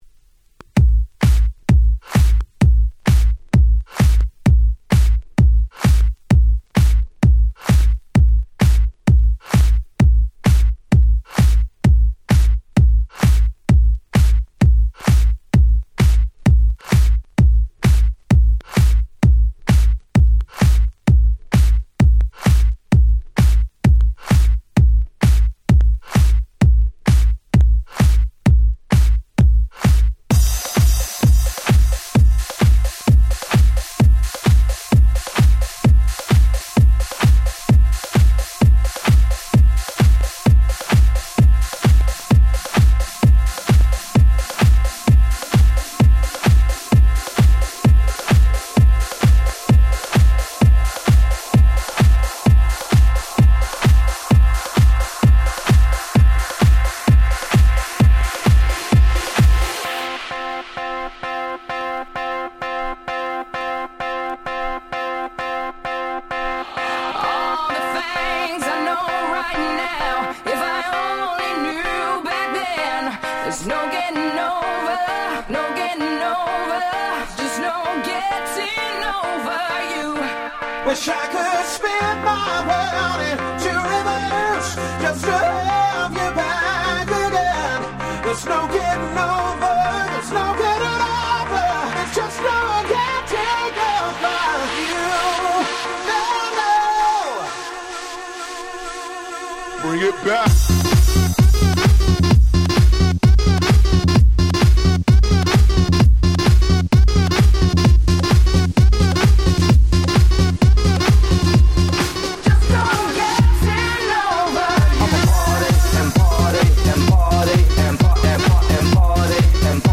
11' Super Hit EDM / R&B / Hip Hop !!